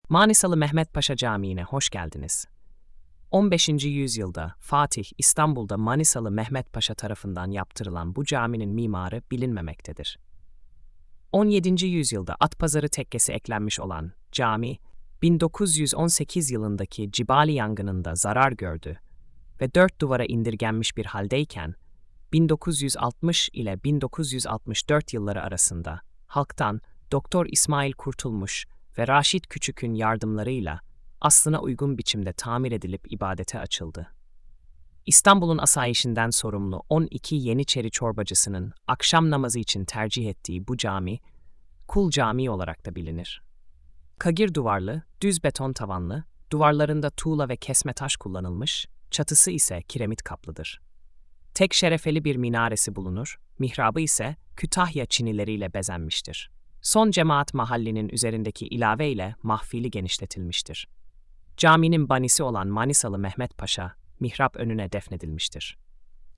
Sesli Anlatım